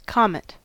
comet-us.mp3